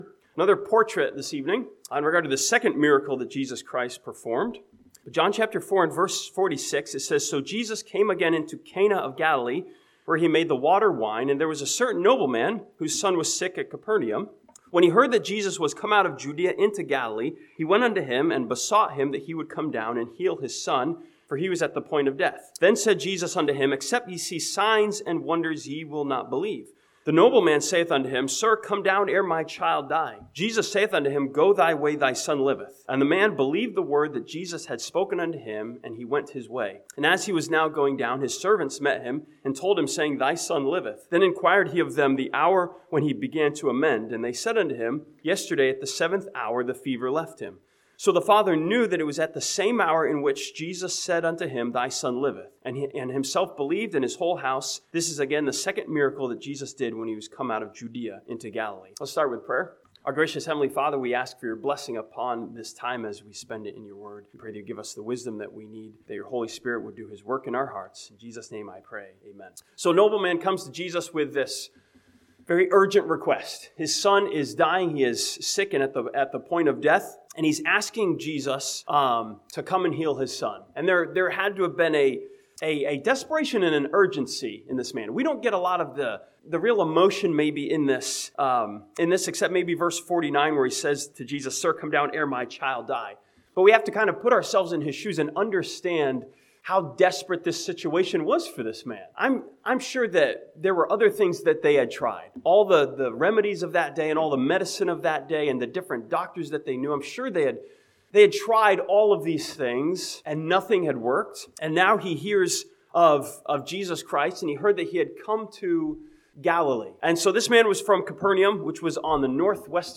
This sermon from John chapter 4 studies the nobleman's unseeing faith as he simply believed the words that Jesus spoke.